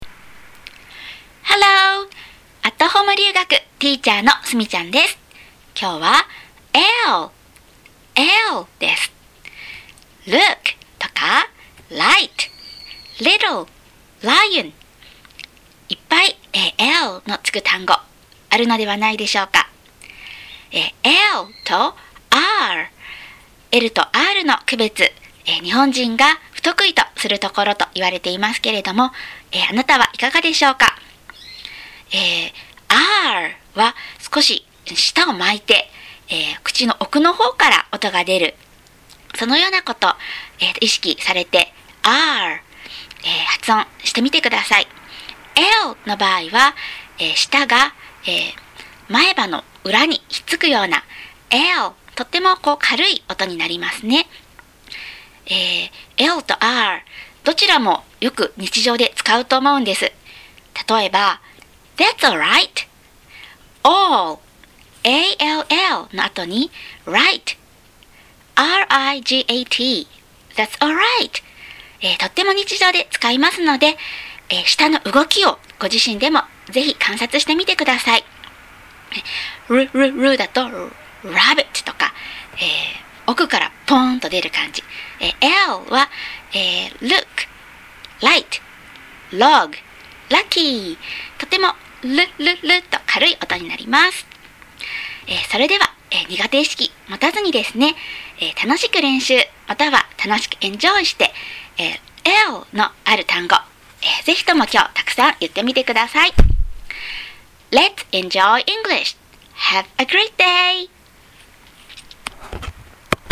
Lは、舌を前歯の奥に当てて出す軽い音。
【親子でフォニックス】